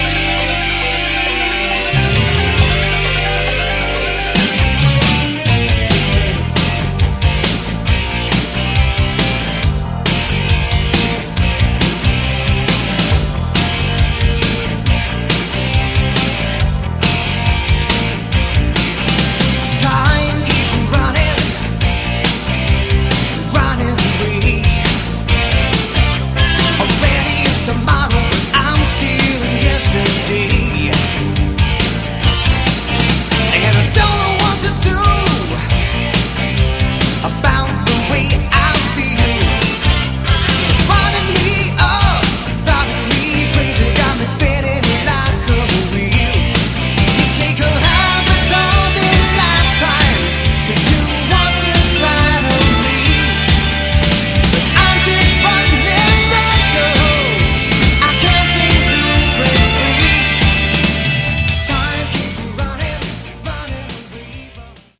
Category: AOR
vocals, bass
drums, backing vocals
guitars, backing vocals
keyboards, backing vocals